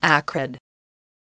Audio Pronunciation of Acrid